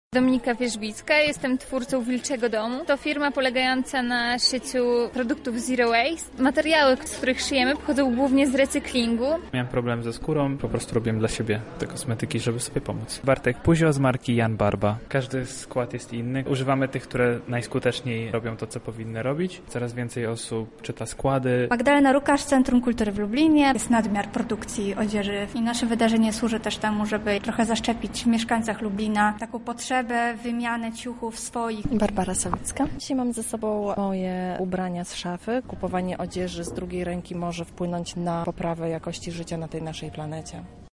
Z uczestnikami rozmawiała nasza reporterka: